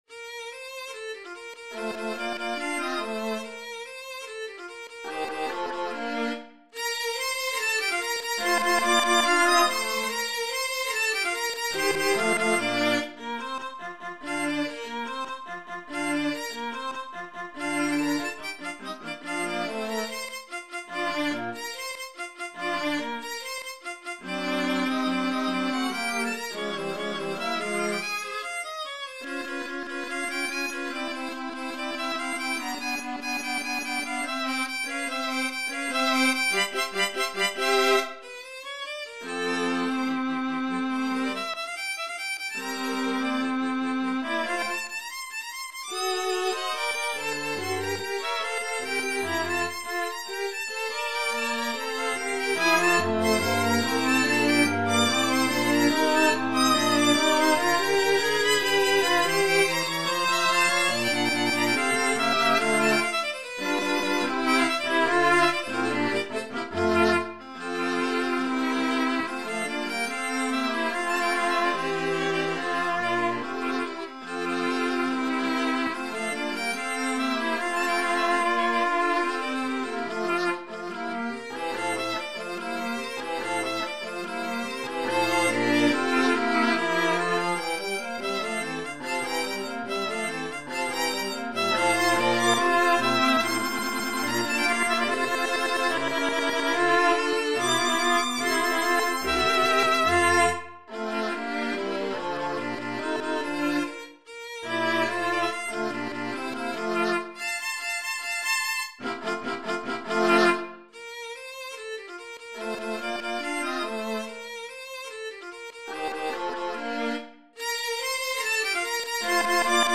KV 458 String Quartet in B flat major "The Hunt" - Allegro assai   [stream]